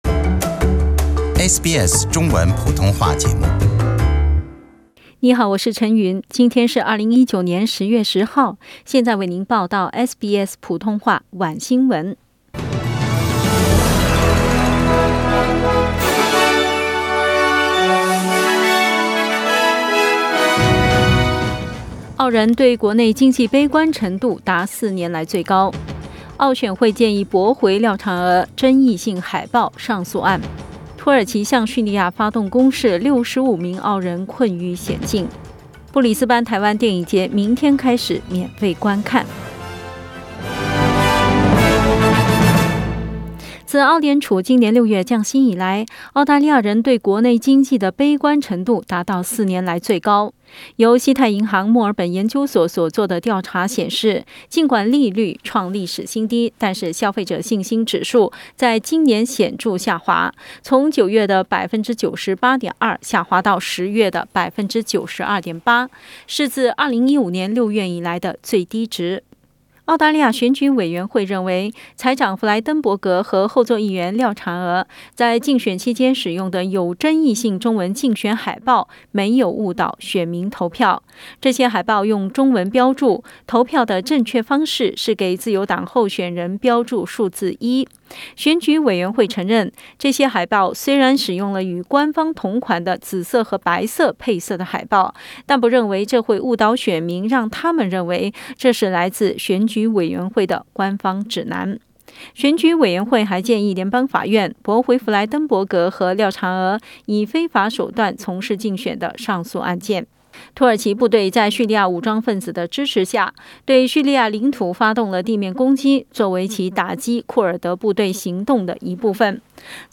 SBS晚新闻 （10月10日）